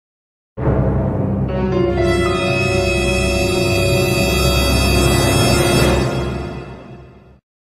Long Suspense sound effect
Thể loại: Âm thanh meme Việt Nam
Description: Một hiệu ứng âm thanh meme dài, đầy kịch tính, giúp tăng độ căng thẳng, kéo dài khoảnh khắc hồi hộp trong các cảnh phim Thriller, Horror, Mystery. Âm thanh thường bắt đầu bằng một vibration âm trầm nhẹ, rồi tăng dần độ căng, cuối cùng nhấn mạnh cao trào khi chuyển đến tình huống bất ngờ.
long-suspense-sound-effect-www_tiengdong_com.mp3